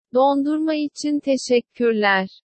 translate_tts-1_ajG9tfZ.mp3